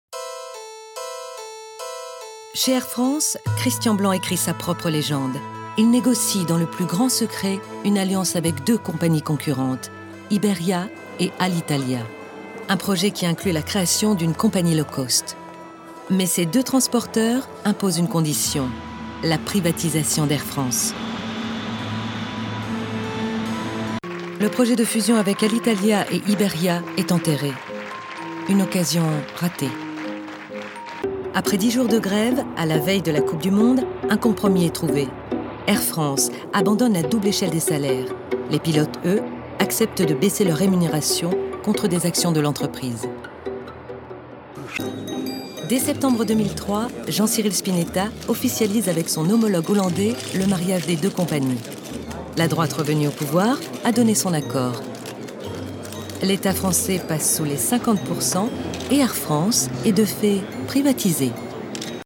Comédienne, comédienne voix off ......et aussi formatrice en communication Orale et comportementale.